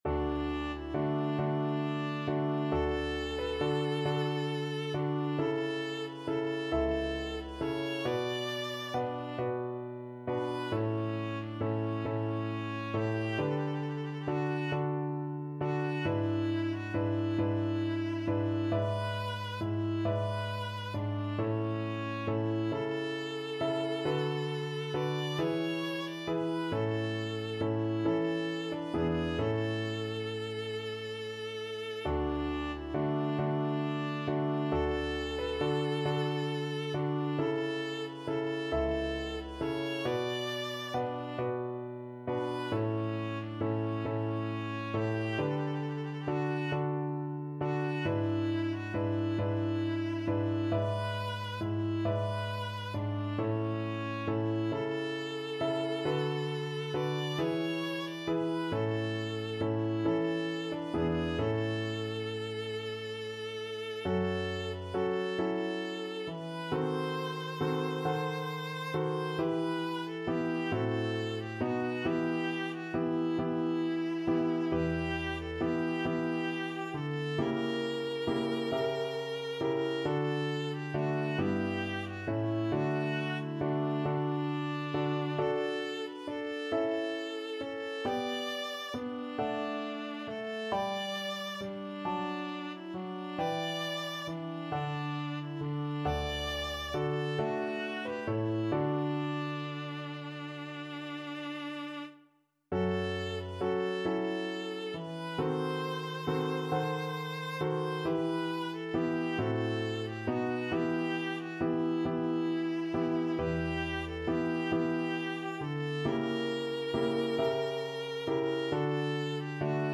12/8 (View more 12/8 Music)
II: Larghetto cantabile .=45
Classical (View more Classical Viola Music)